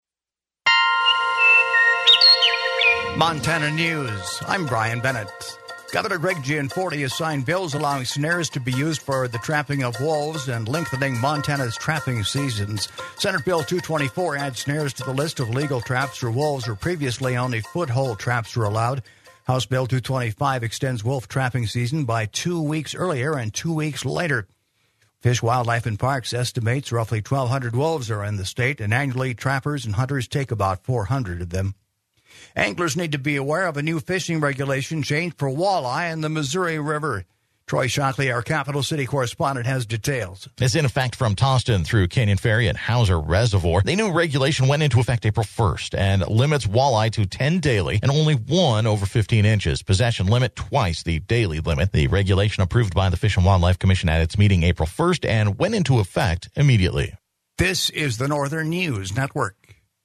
In: News Headlines